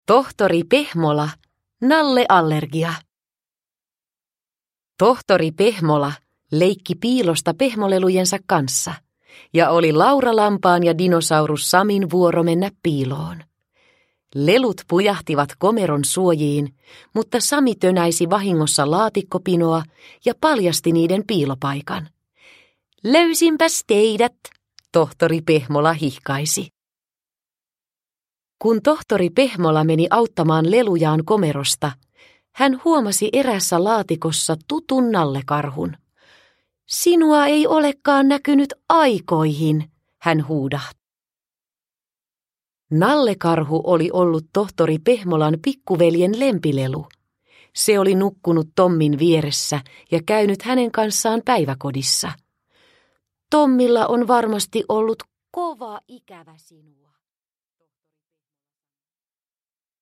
Tohtori Pehmola. Nalleallergia – Ljudbok – Laddas ner